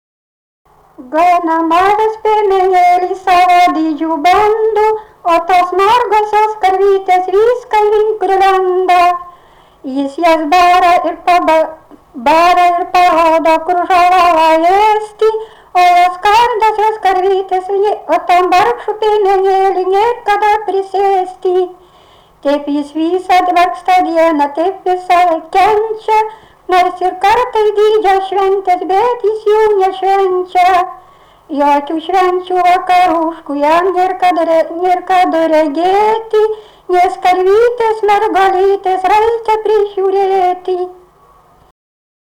Dalykas, tema daina
Erdvinė aprėptis Suvainiai
Atlikimo pubūdis vokalinis